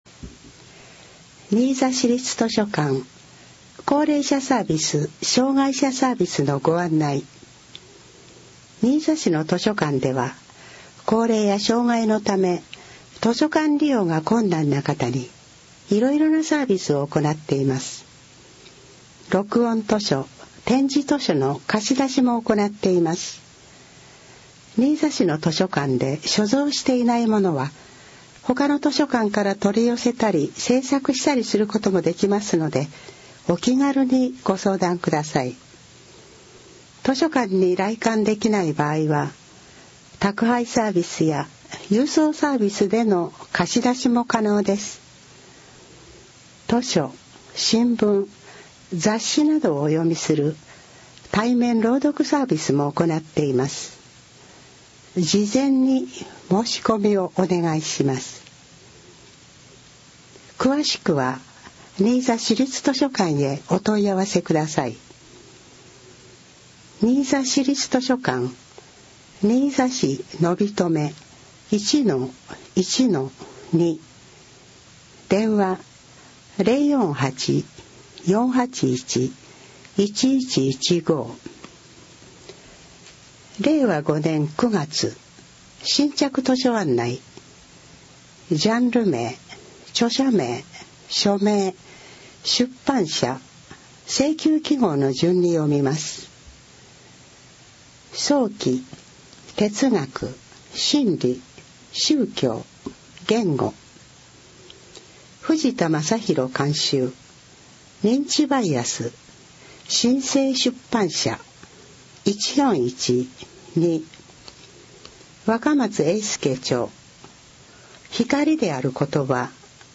新着図書案内（音声版）は、図書館朗読ボランティアグループ「こだま」の皆さんが、 「図書館だより」の新着図書案内を朗読し、CDに録音しています。